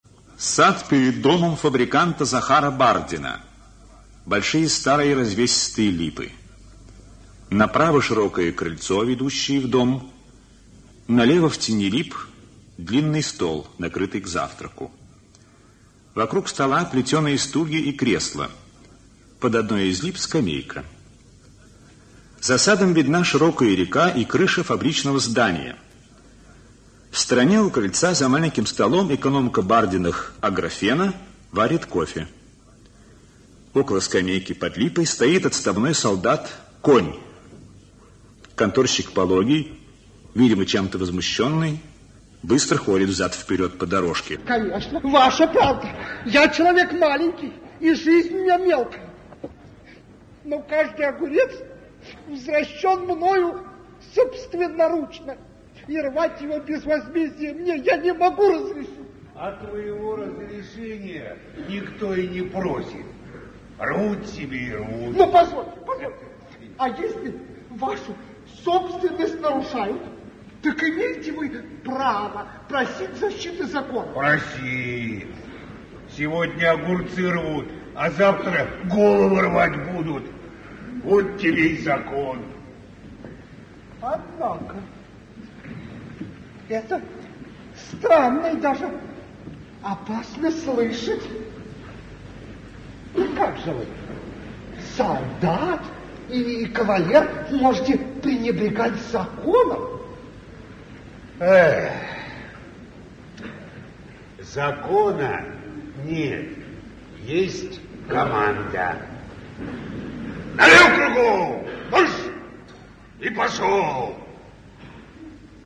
Аудиокнига Враги (спектакль) | Библиотека аудиокниг
Aудиокнига Враги (спектакль) Автор Максим Горький Читает аудиокнигу Актерский коллектив.